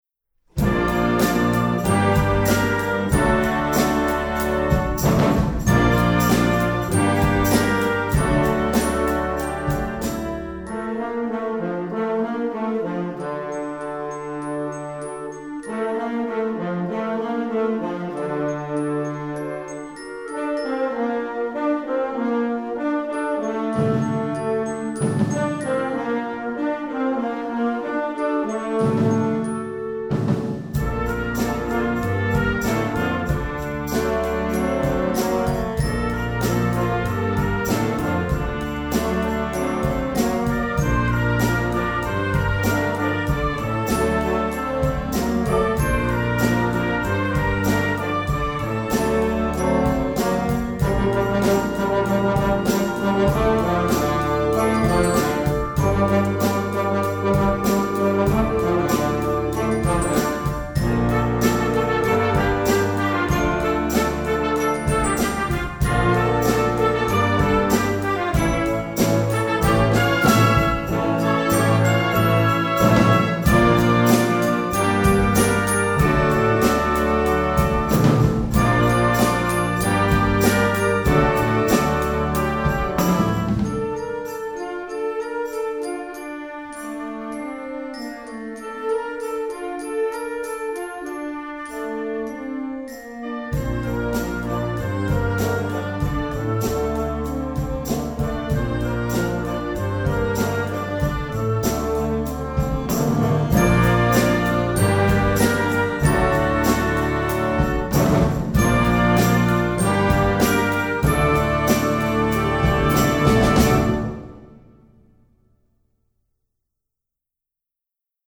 film/tv, pop, rock, movies